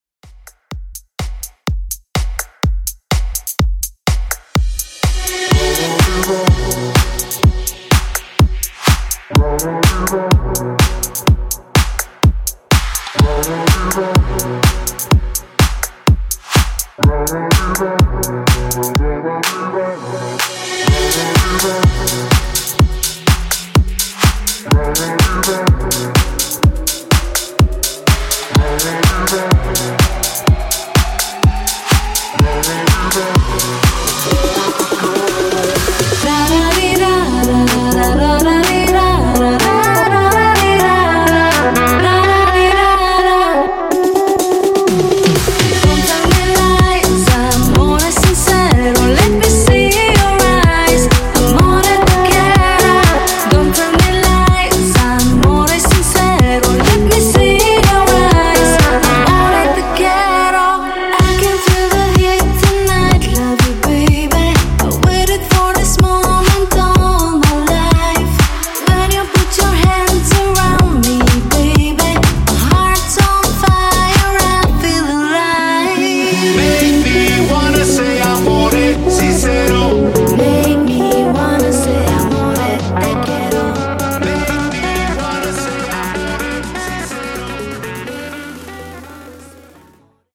Dance House)Date Added